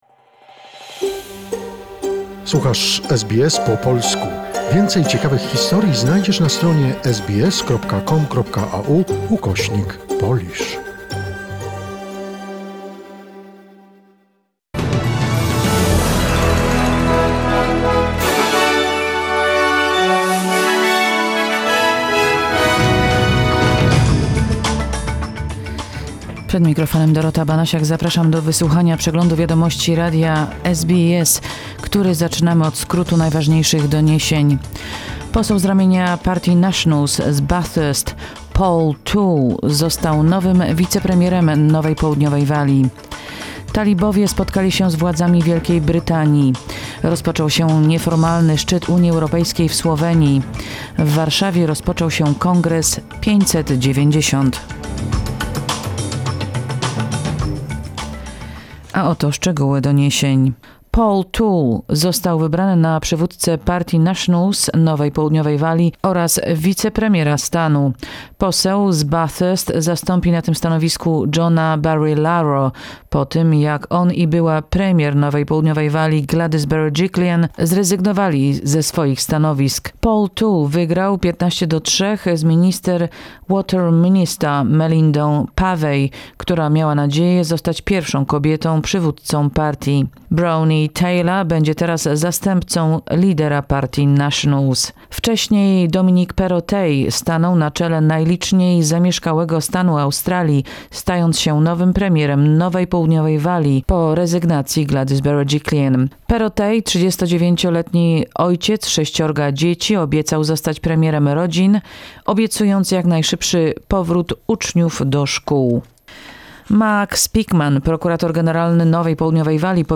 SBS News in Polish, 6 October 2021